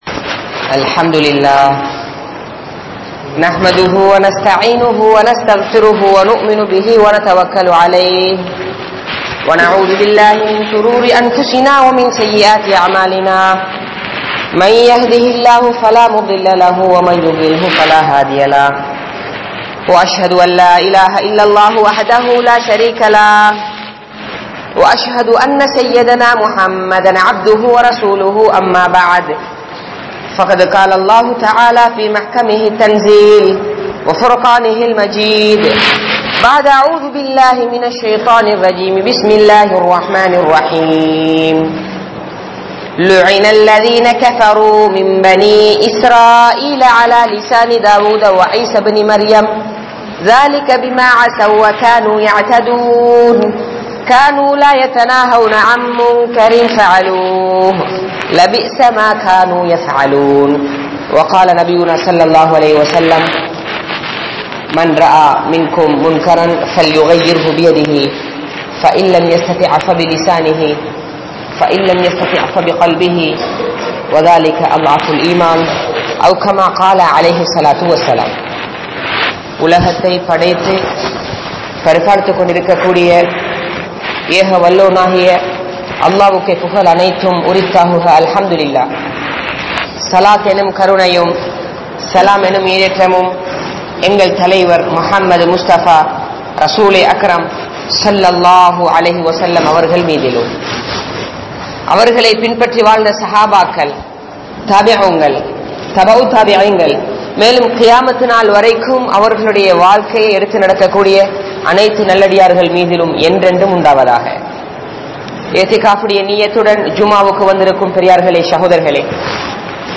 Paavangalum Allah`vin Alivuhalum (பாவங்களும் அல்லாஹ்வின் அழிவுகளும்) | Audio Bayans | All Ceylon Muslim Youth Community | Addalaichenai
Grand Jumua Masjidh(Markaz)